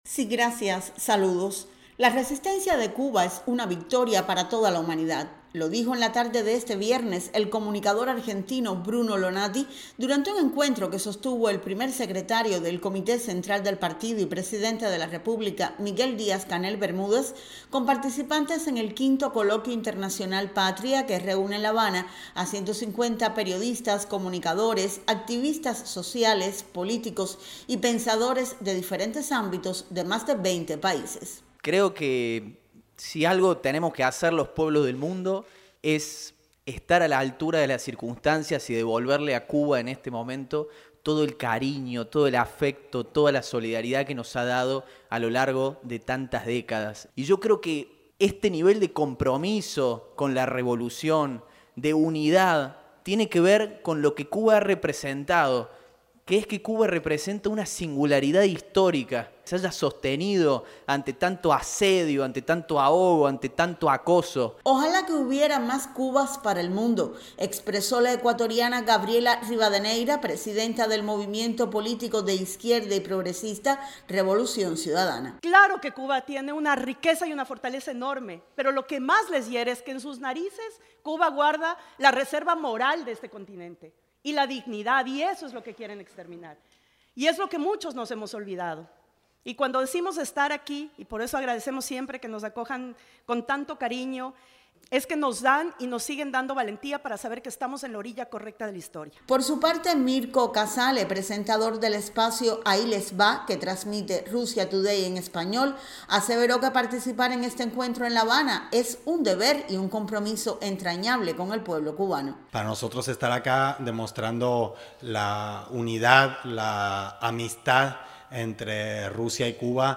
Defiendan a Cuba, invitó este viernes en la tarde, en un encuentro desde el Palacio de la Revolución, el Primer Secretario del Comité Central del Partido Comunista y Presidente de la República, Miguel Díaz-Canel Bermúdez, a participantes de la V edición del Coloquio Internacional Patria, porque ustede saben y sienten, dijo, que la Isla representa mucho para quienes creen que un mundo mejor es posible.